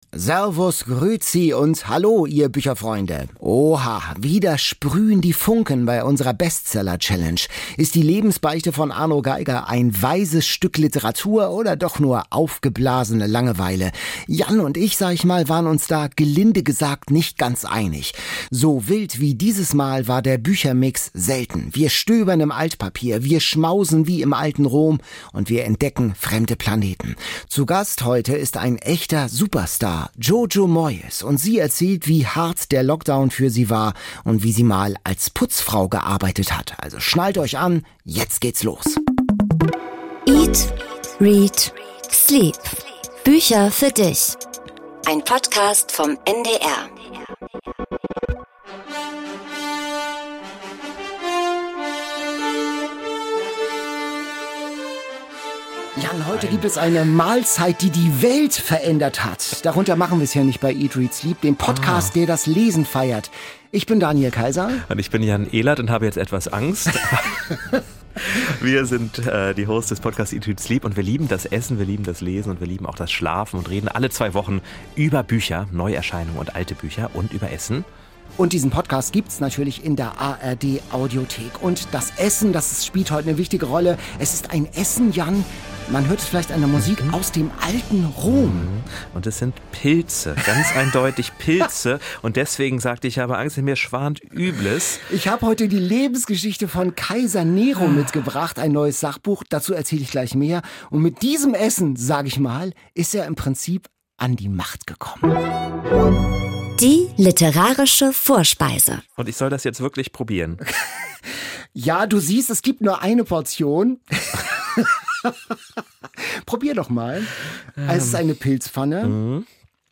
Außerdem feiern die Hosts Peter Ustinov, im Quiz geht es unter die Erde und rauf bis zum Mond - und Bestseller-Autorin Jojo Moyes erzählt im Studio von ihrer Abneigung gegen das Putzen und warum sie Tennisschuhe statt High Heels trägt.